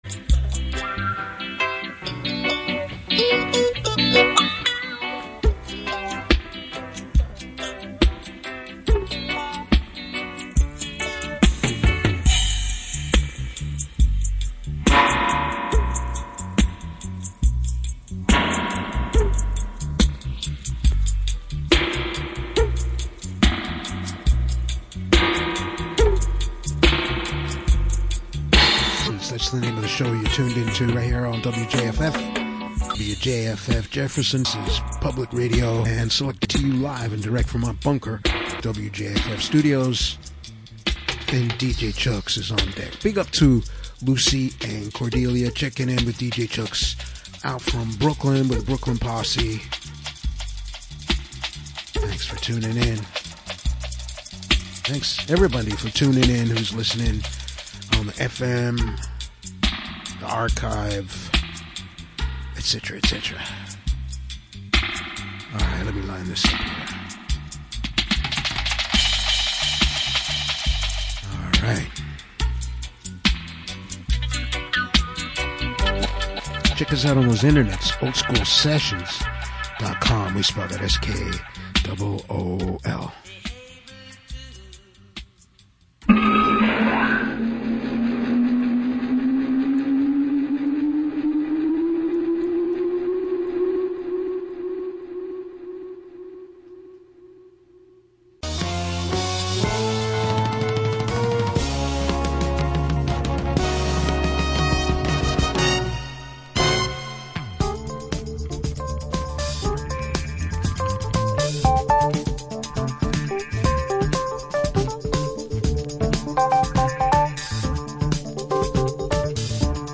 music straight from the roots of Hip Hop